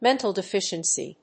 アクセントméntal defíciency